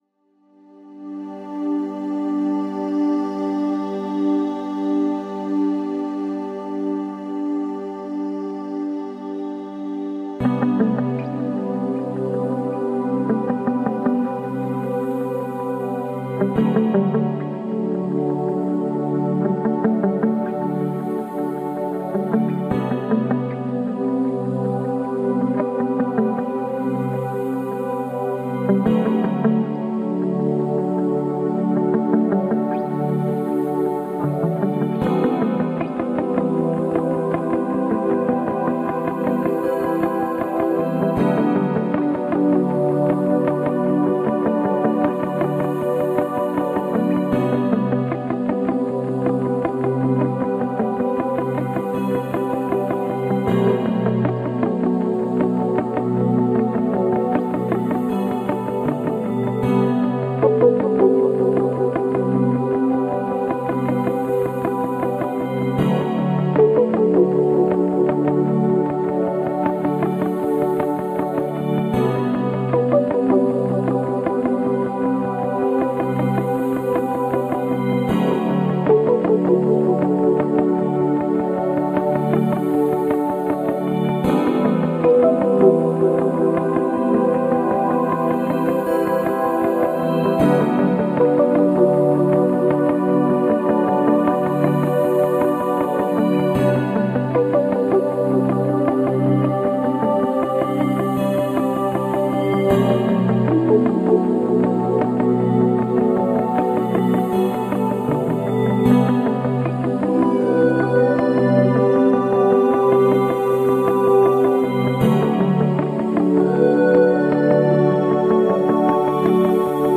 Wyciszenie, relaks, medytacje.